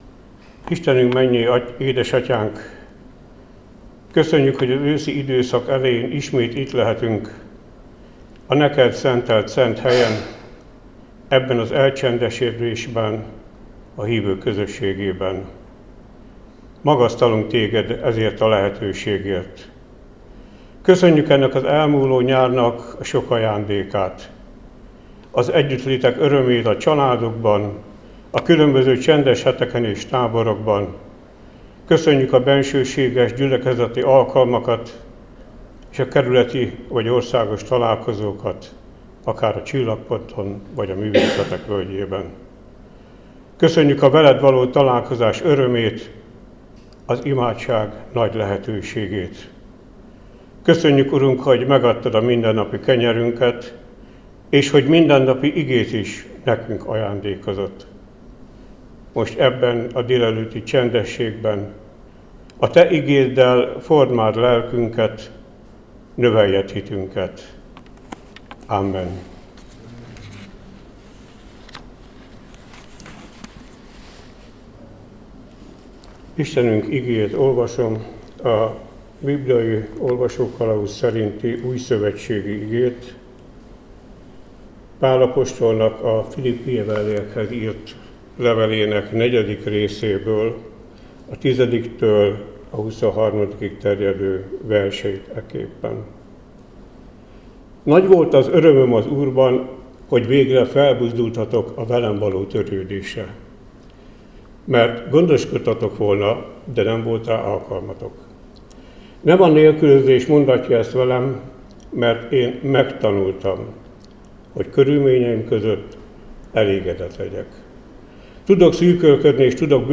Áhítat, 2025. szeptember 9.